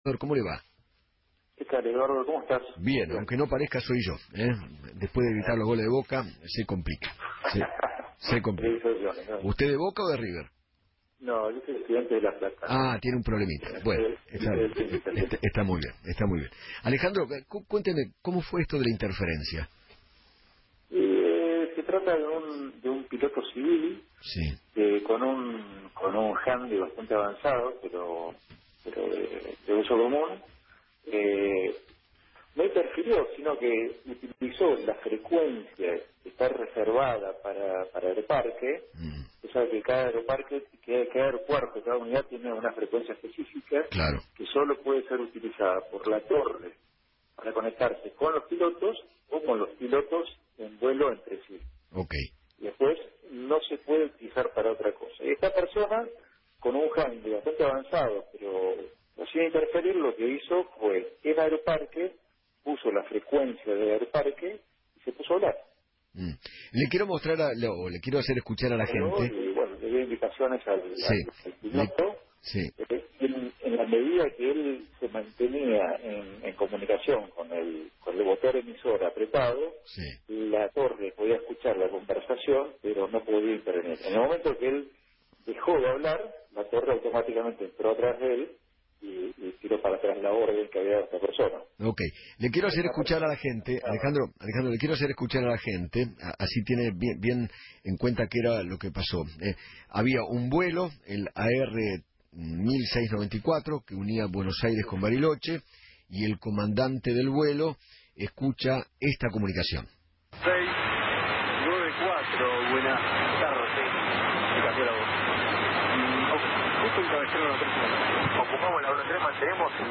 Alejandro Itzcovich, Director de la PSA, habló en Feinmann 910 y dijo que “Un piloto civil, con un handy avanzado utilizó la frecuencia de Aeroparque para conectarse con los pilotos en vuelo.